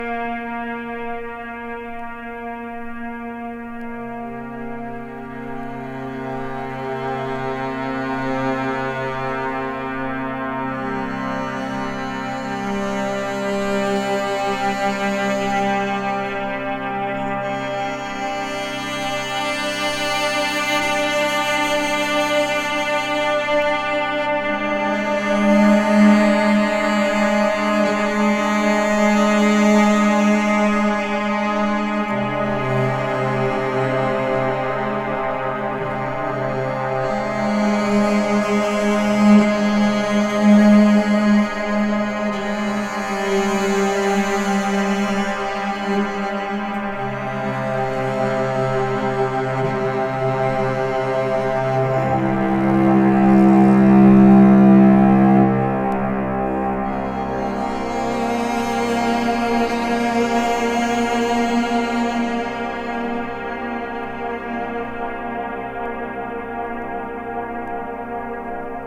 チェロ、ギター、シンセ(Casio MT-30)という編成もグッときます！深ーい世界に引きずり込まれます。